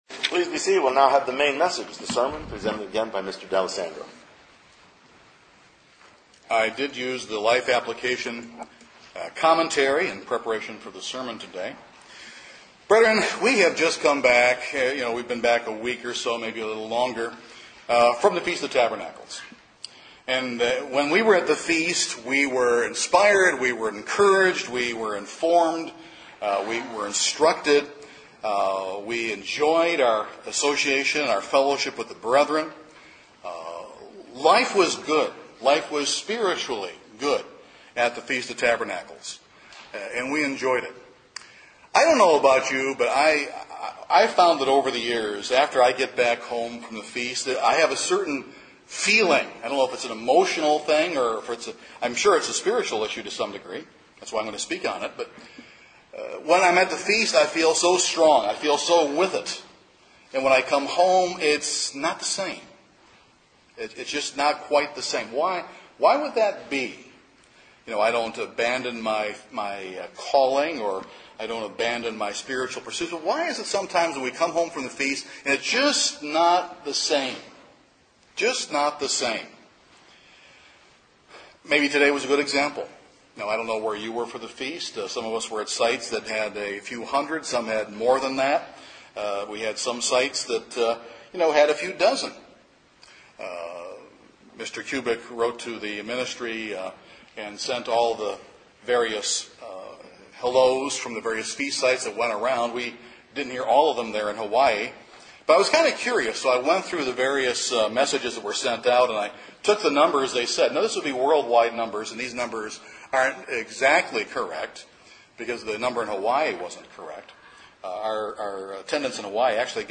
We need to identify the "passion killers" in our lives and strike while the passion of this year's Feast remains high in our hearts and minds. This sermon will identify several of these "passion killers" and what we can do about the situation.